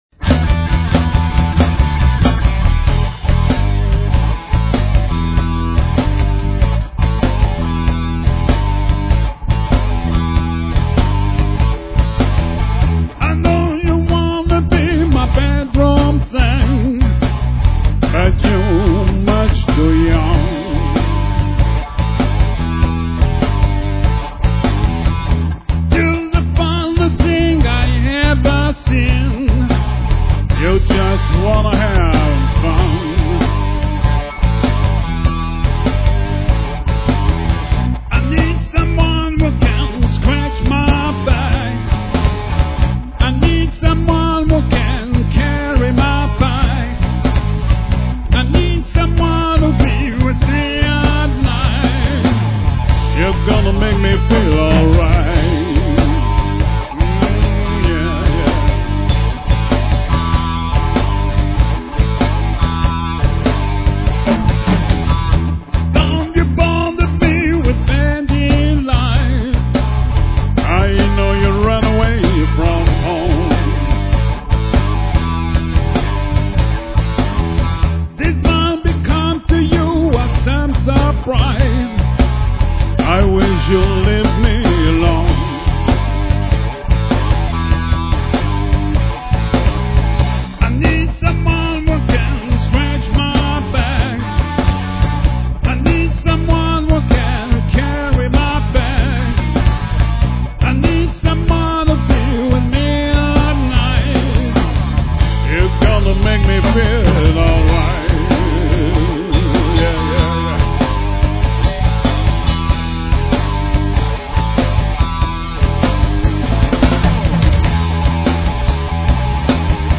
du répertoire Blues.
C'est du mp3, donc un peu compréssé,
d'ou la qualité moyenne du son…